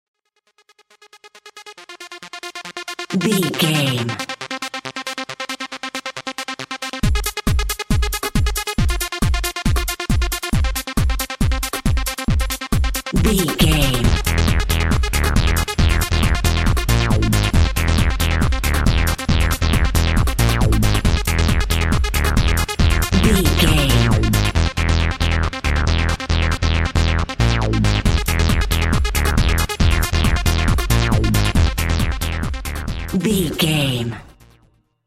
Groovy Techno.
Aeolian/Minor
driving
energetic
futuristic
hypnotic
drum machine
synthesiser
electronic
sub bass
synth lead